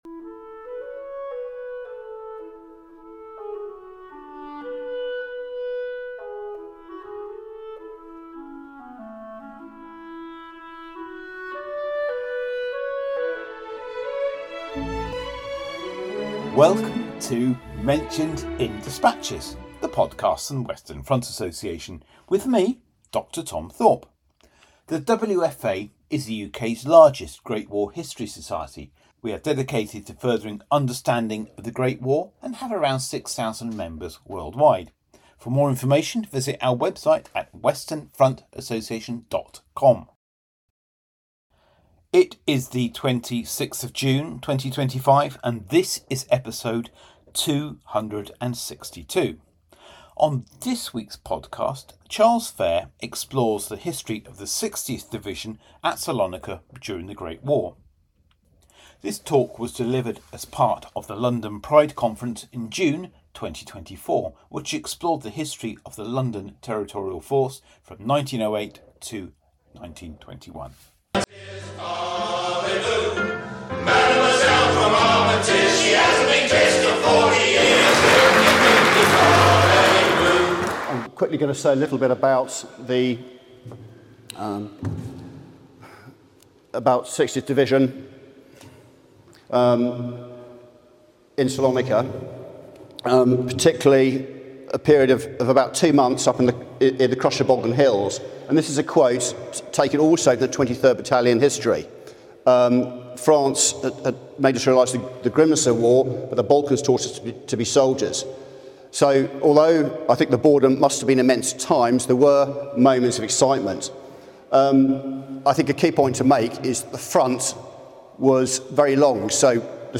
These operations provided valuable preparation for later service in Palestine. This talk was part of the London Pride Conference held in June 2024.